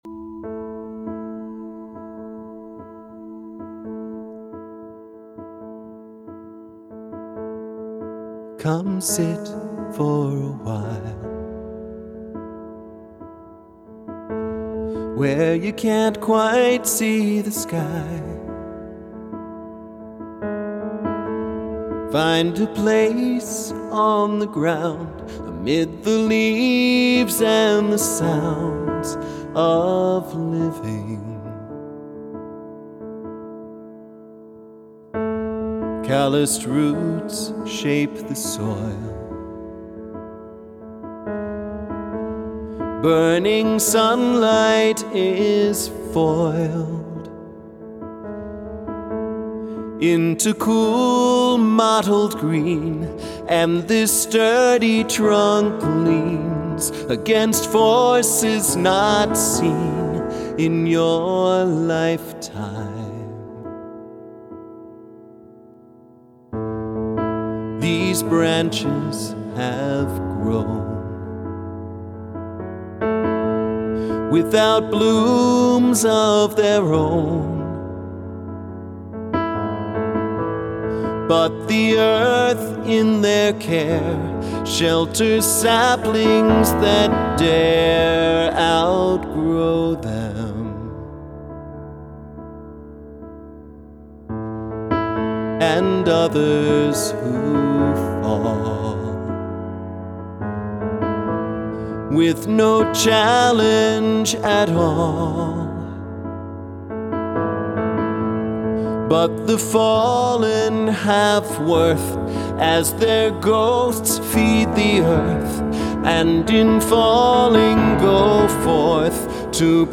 This is a solo arrangement.